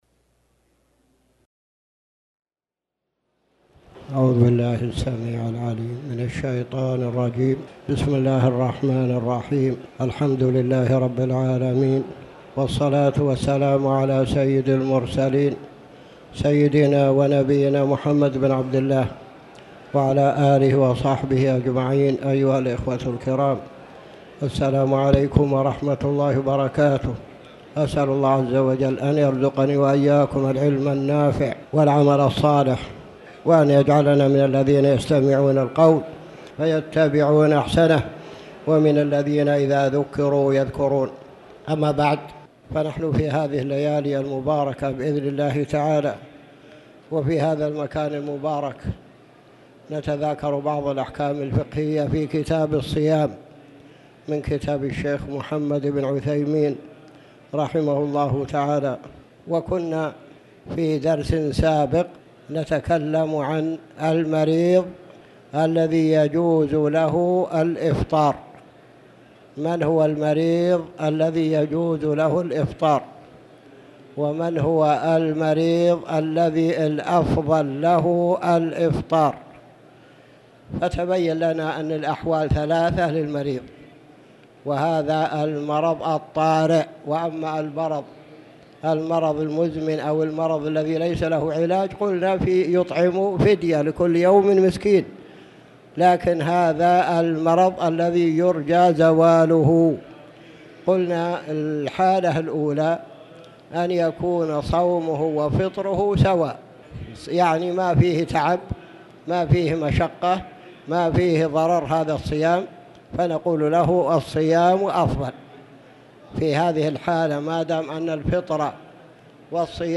تاريخ النشر ١٧ جمادى الأولى ١٤٣٩ هـ المكان: المسجد الحرام الشيخ